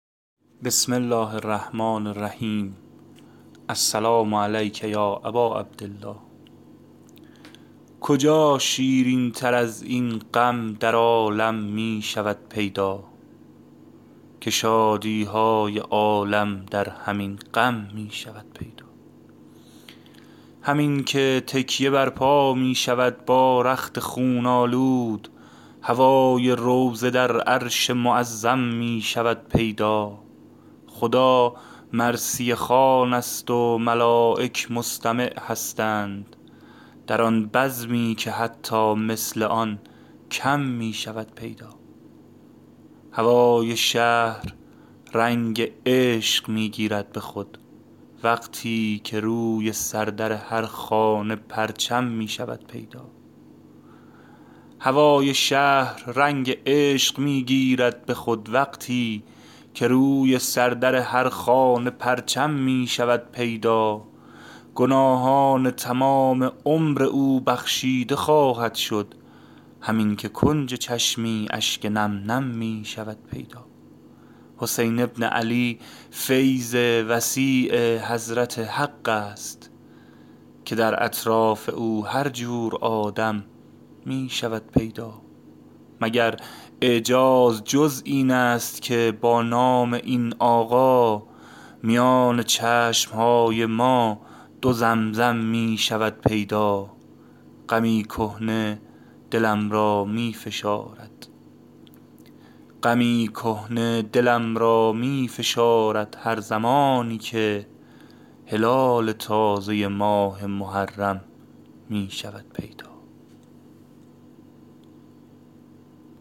شعر خوانی محرم